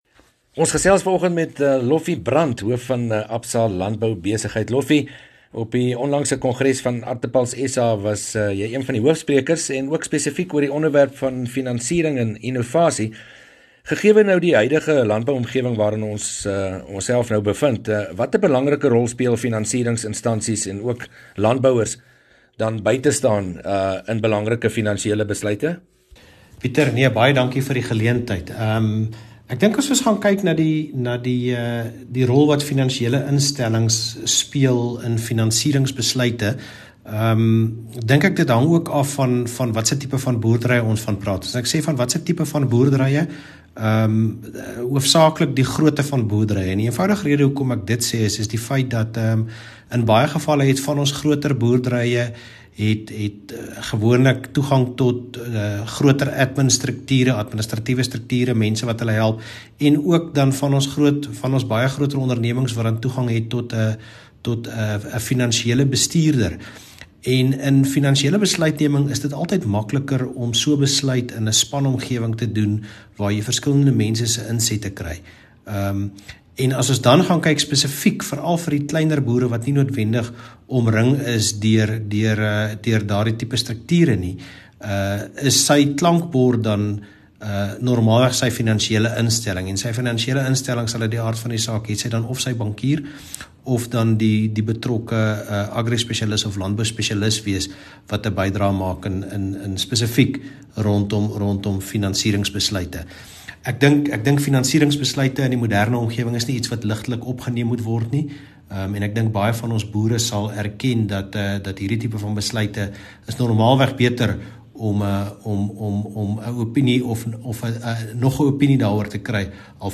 gesels met 'n Landboubestuurder van een van die handelsbanke